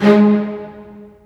ZG3 MARC G#3.wav